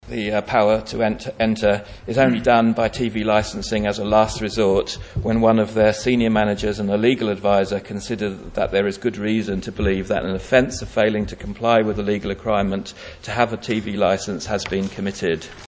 Policy and Reform Minister Chris Thomas said the rules are clear: